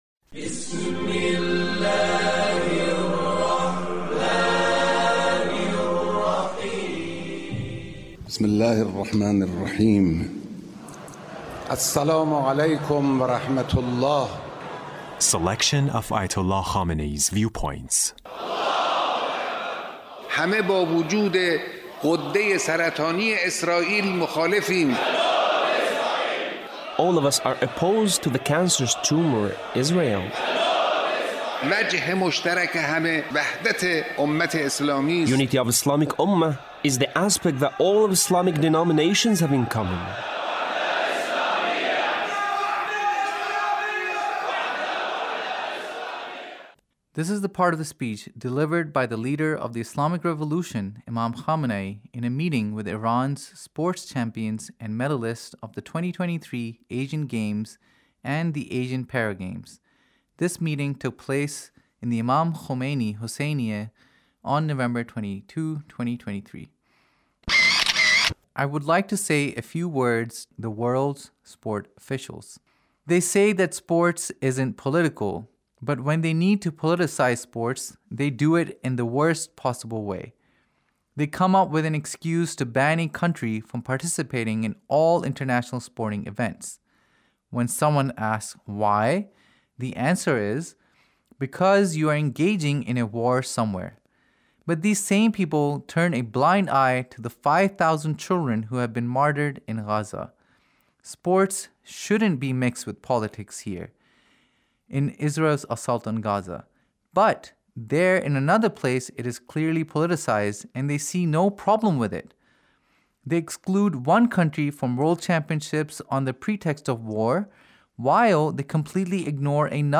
Leader's Speech in a meeting with Iran’s sports champions and medalists